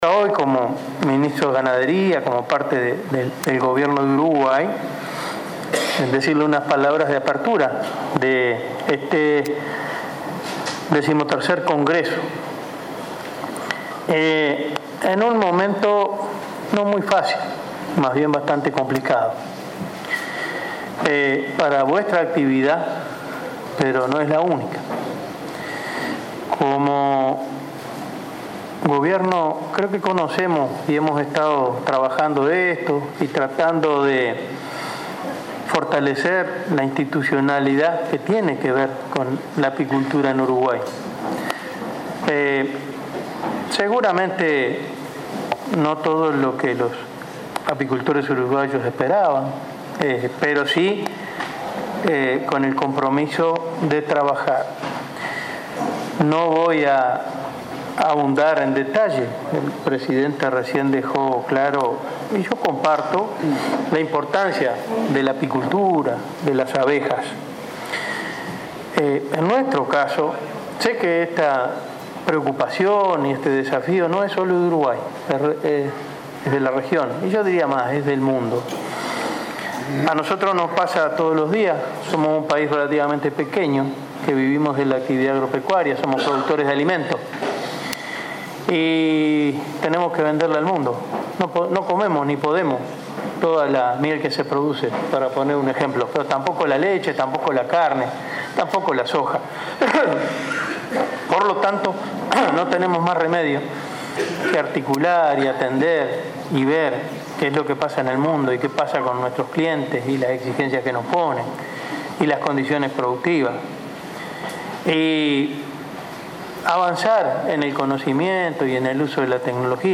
“Sabemos los problemas que enfrenta el sector apícola, por eso, cuenten con el Ministerio de Ganadería para continuar trabajando en mercados, institucionalidad y diagnósticos”, manifestó el titular de Ganadería, Enzo Benech, durante la inauguración del XIII Congreso Latinoamericano de Apicultura.